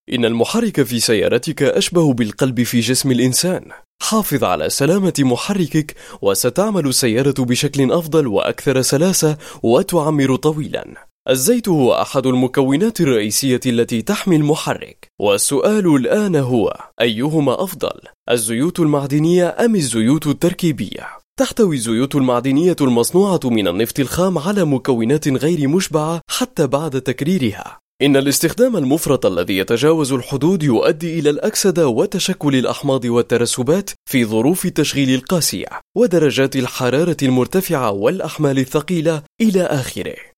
I am a professional arabic voice over talent.
Sprechprobe: Sonstiges (Muttersprache):